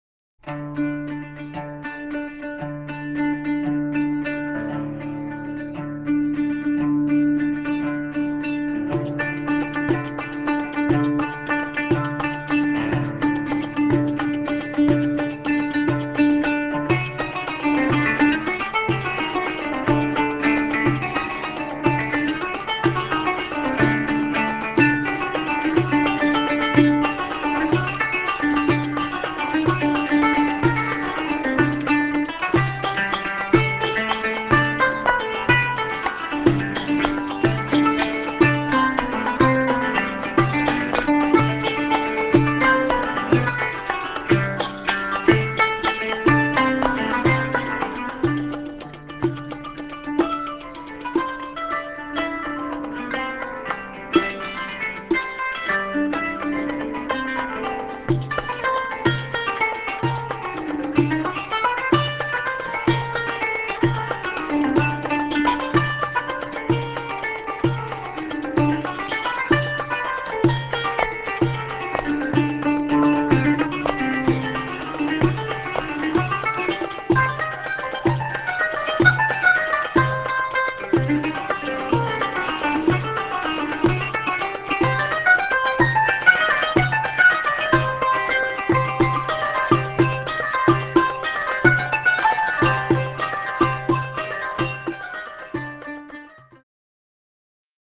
qanun (Middle Eastern zither)
extended and intricate solos
in 7/8 time